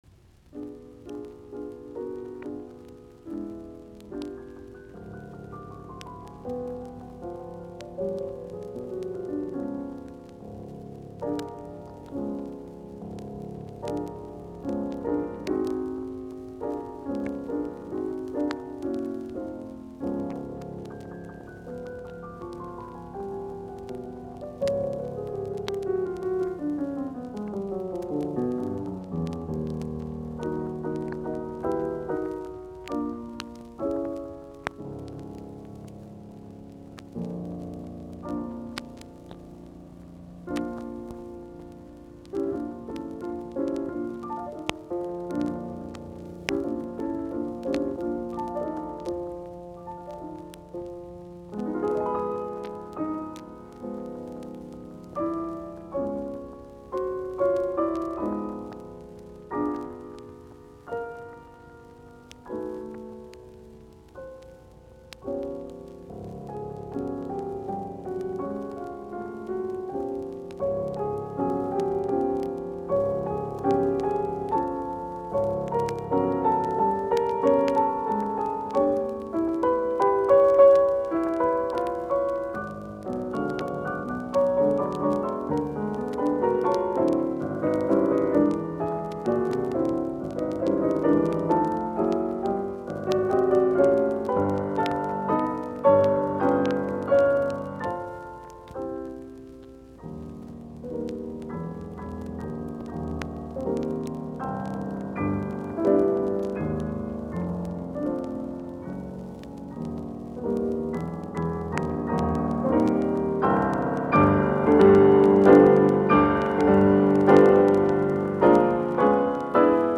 Soitinnus : Piano